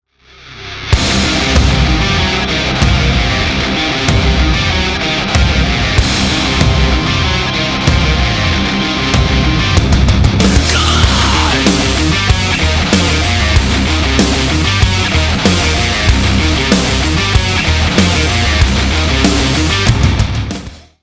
• Качество: 320, Stereo
громкие
Драйвовые
без слов
электрогитара
Post-Hardcore
инструментал
Стиль: Melodic Metalcore